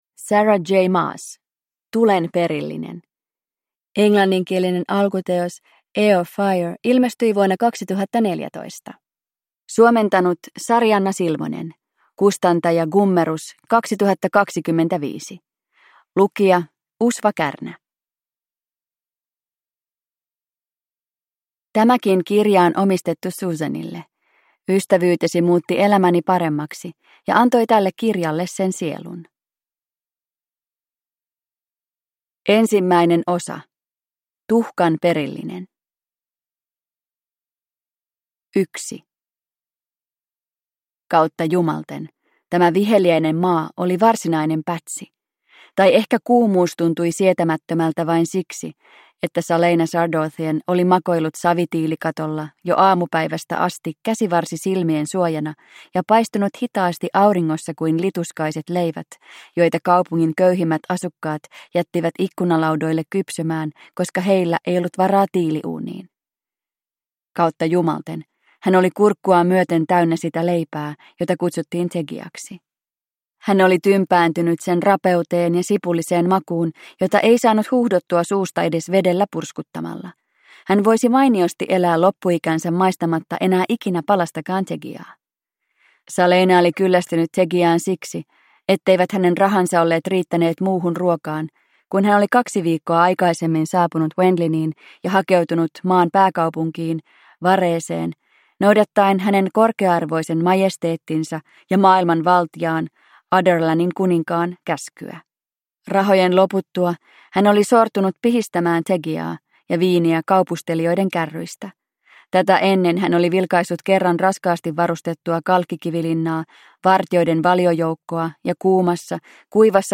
Throne of Glass - Tulen perillinen – Ljudbok